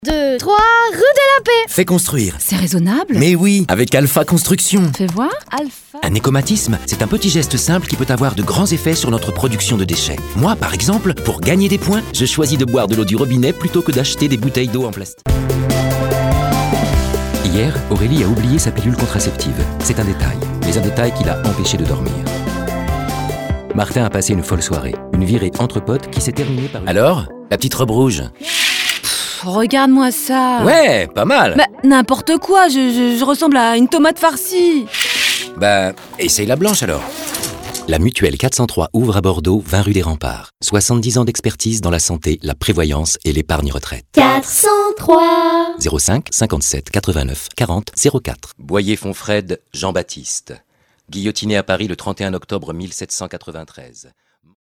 Bande démo livres audio